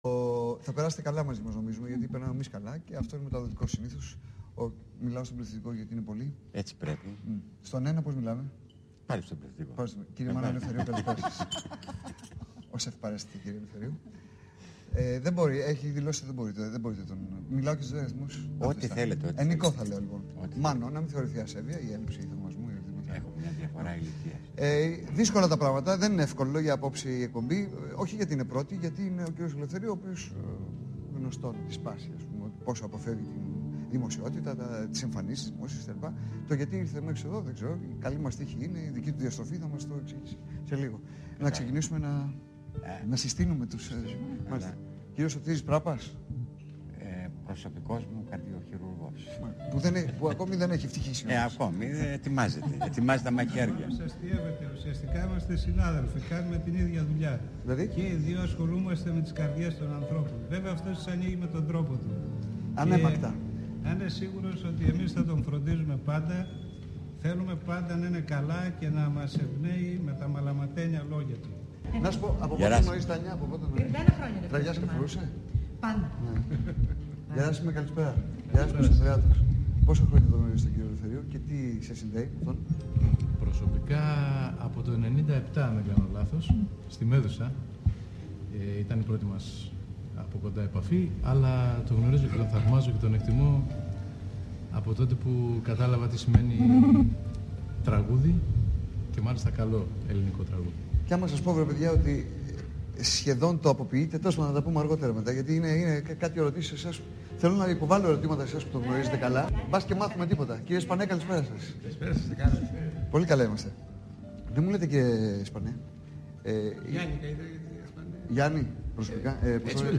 Ψυχαγωγική εκπομπή: «Στην υγειά μας» (ώρα έναρξης εκπομπής: 22:00)